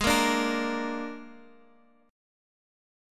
Absus2b5 chord